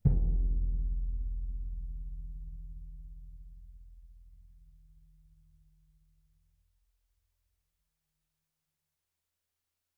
Orchestral Bass
bassdrum_hit_mf2.wav